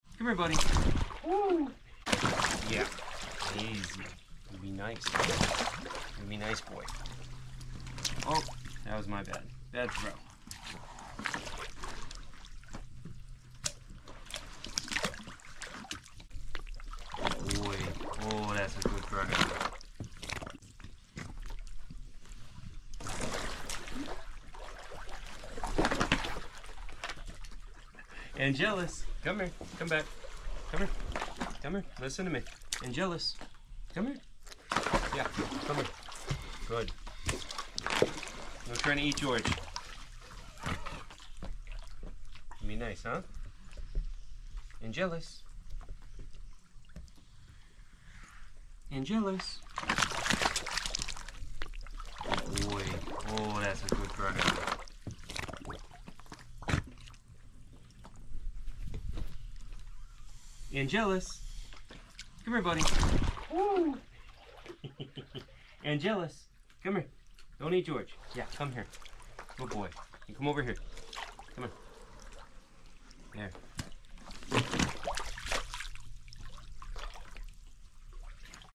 Well the truth is there’s no point in speaking in any other way, jumping around screaming like an idiot will only make the situation worse, it’s much better to be calm and collected. These are apex predators, no doubt about it, but that doesn’t mean they can’t hear my words and tone when speaking to them.